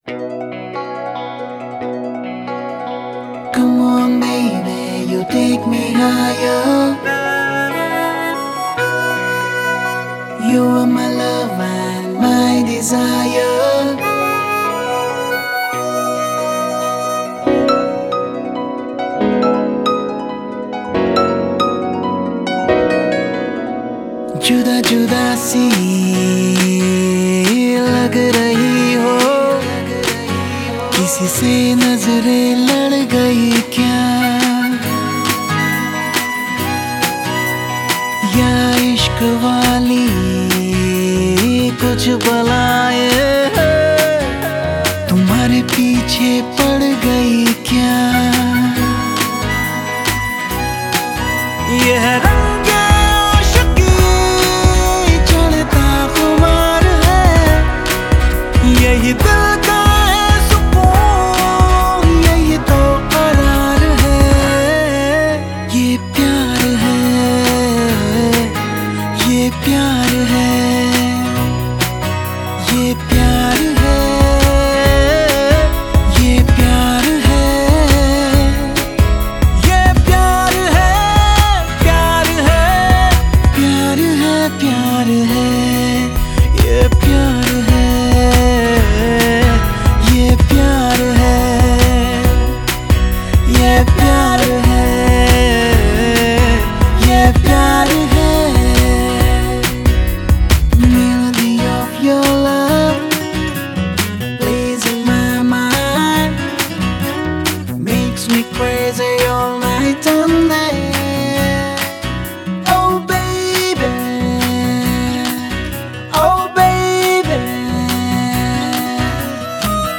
Bollywood Mp3 Music